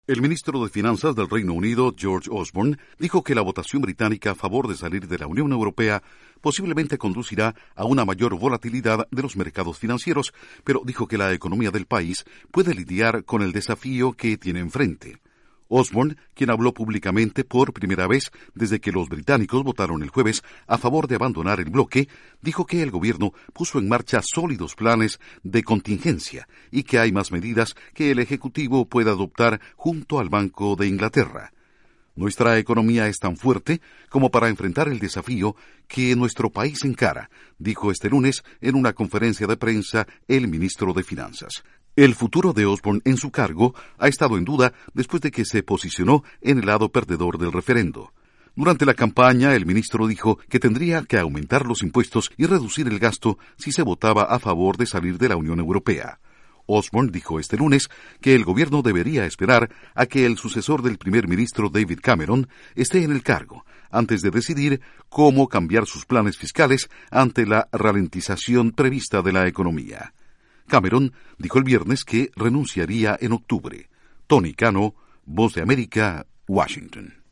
El Ministro de Finanzas británico prevé volatilidad en los mercados tras la salida del Reino Unido de la Unión Europea. Informa desde la Voz de América en Washington